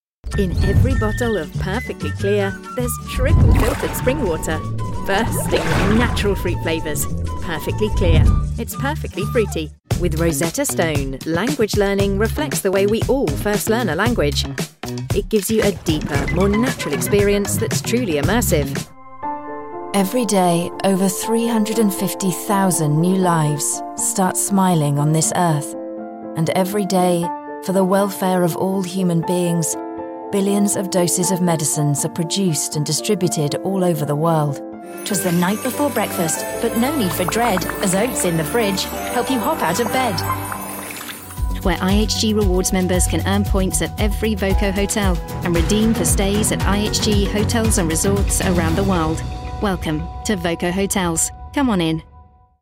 Inglês (britânico)
Autêntico
Esquentar
Genuíno